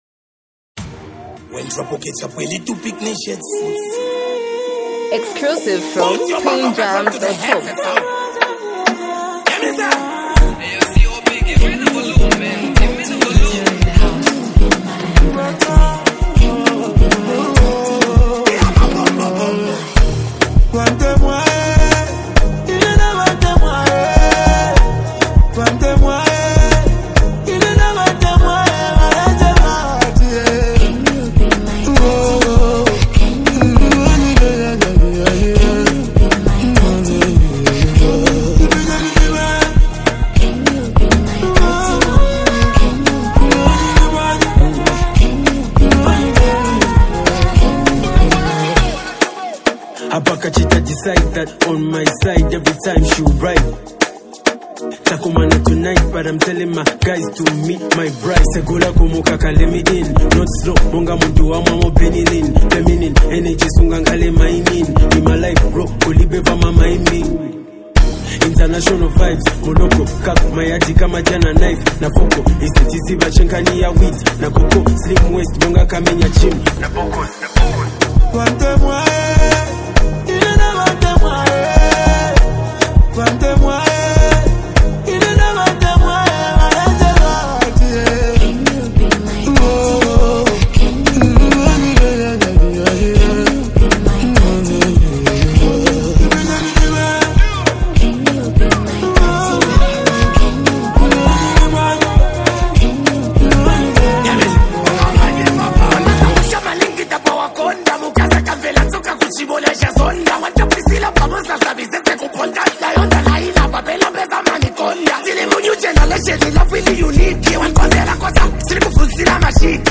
Production-wise, the track is clean and well-structured.